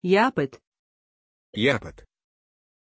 Pronunciation of Japheth
pronunciation-japheth-ru.mp3